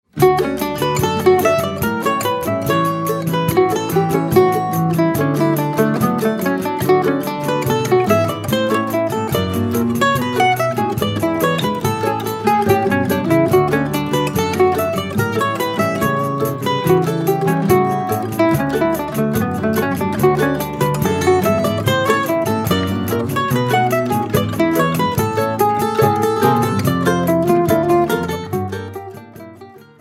bandolim